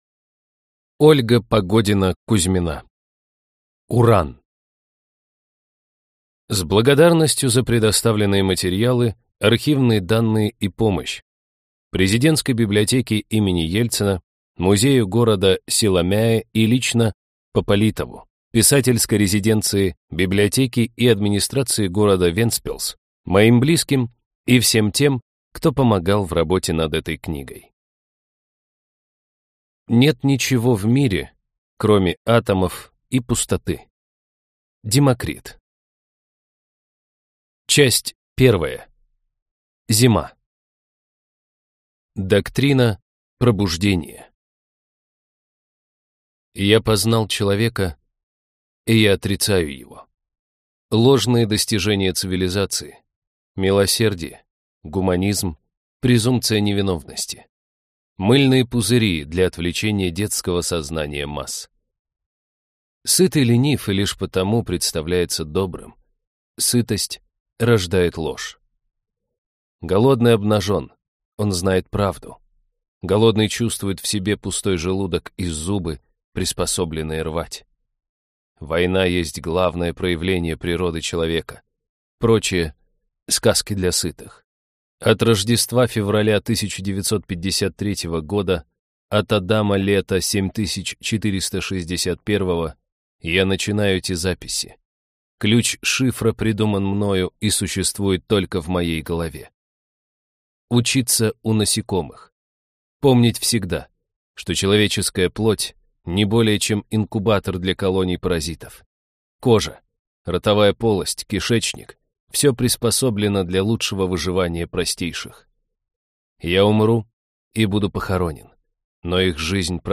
Аудиокнига Уран | Библиотека аудиокниг